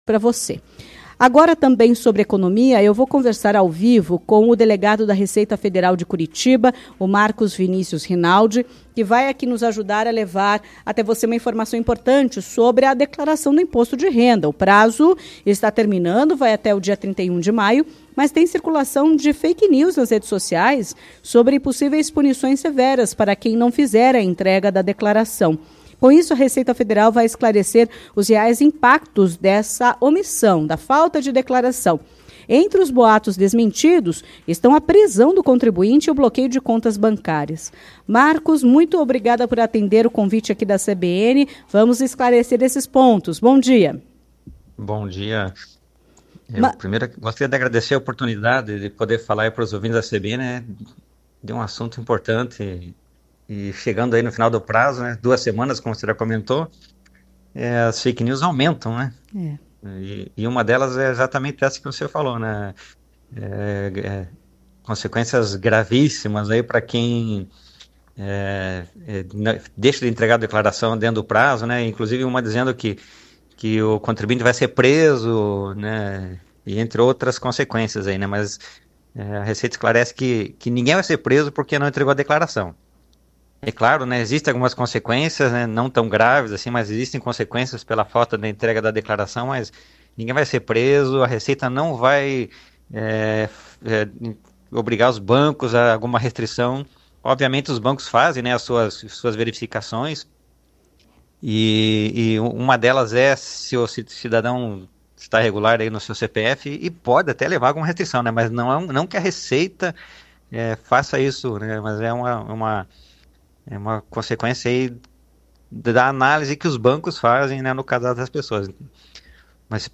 Em entrevista à CBN Curitiba nesta segunda (19), o delegado da Receita Federal de Curitiba, Marcos Vinícius Rinaldi, ressaltou que ninguém vai preso por não entregar declaração do Imposto de Renda e ainda deixou mais recomendações para o envio do documento até o dia 31 de maio.